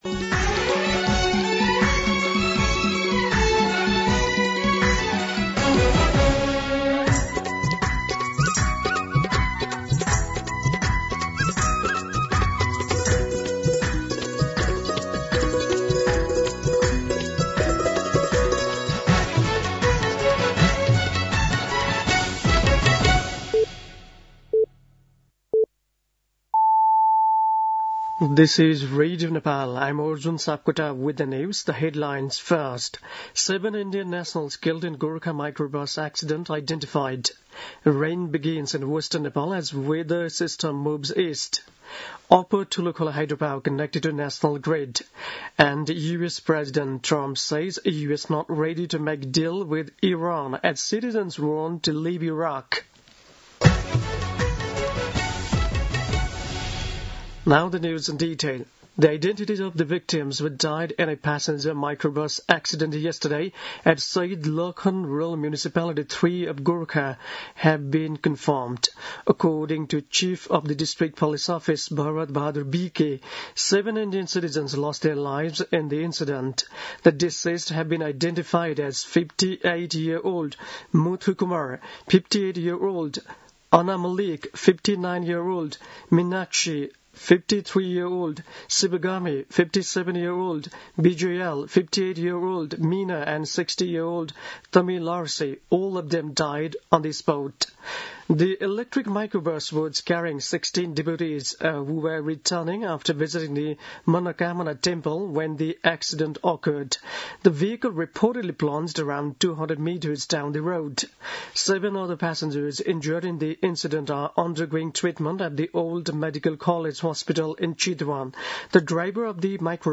दिउँसो २ बजेको अङ्ग्रेजी समाचार : १ चैत , २०८२